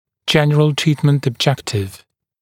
[‘ʤenrəl ‘triːtmənt əb’ʤektɪv] [ɔb-][‘джэнрэл ‘три:тмэнт эб’джэктив] [об-]общая цель лечения